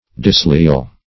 Search Result for " disleal" : The Collaborative International Dictionary of English v.0.48: Disleal \Dis*leal"\, a. [See Disloyal , Leal .] Disloyal; perfidious.